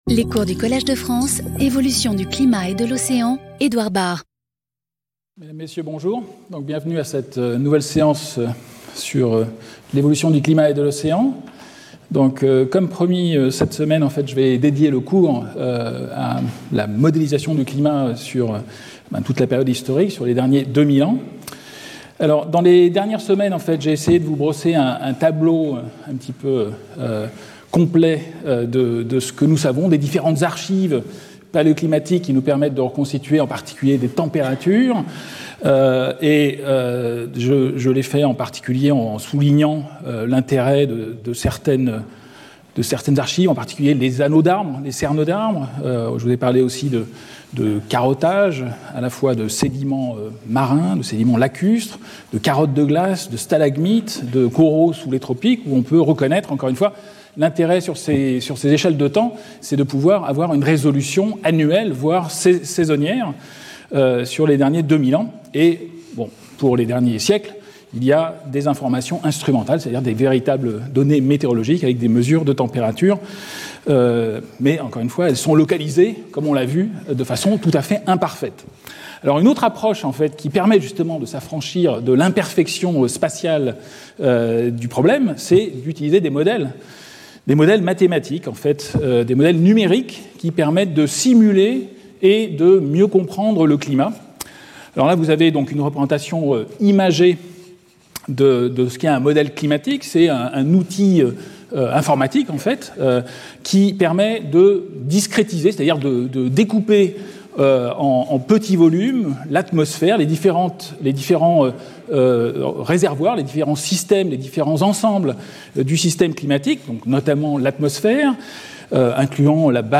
Intervenant(s) Edouard Bard Professeur du Collège de France
Cours